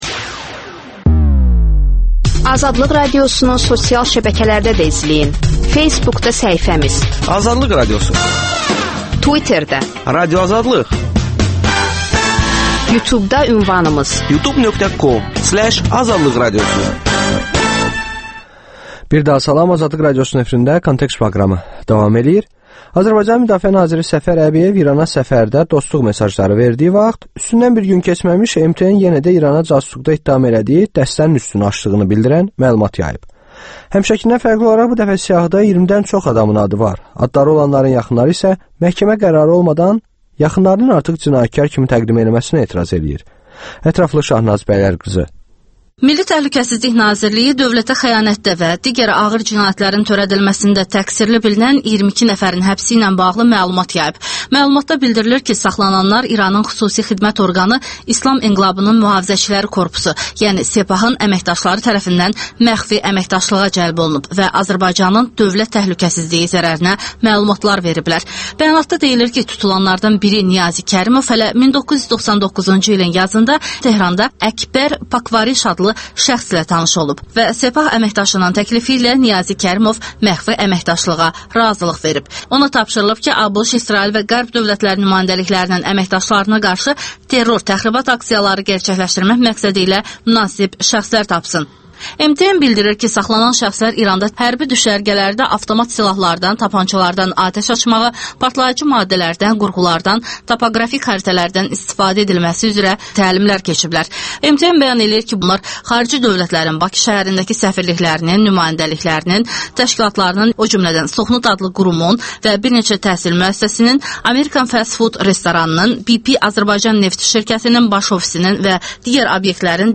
Müsahibələr, hadisələrin müzakirəsi, təhlillər